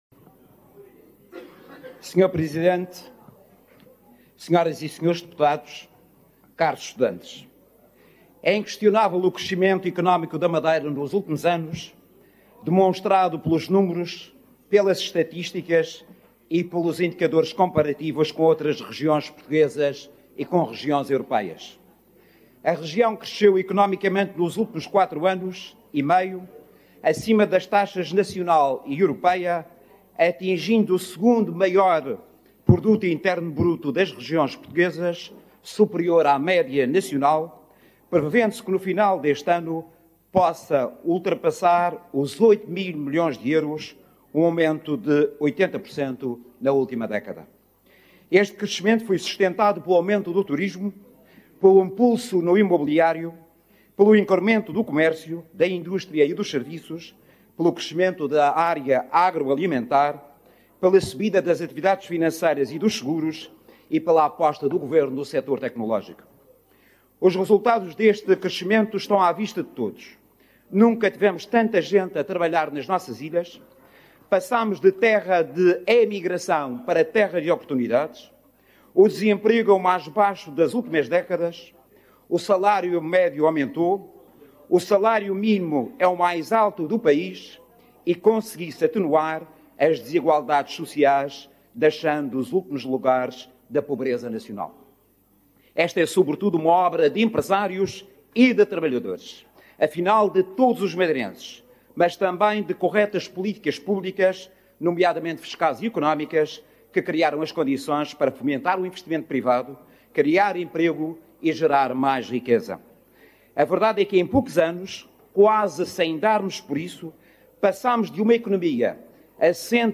Economia com 128 milhões de euros, mais de metade para investimento José Manuel Rodrigues interveio hoje no debate sobre o Orçamento e Plano de Investimentos para 2026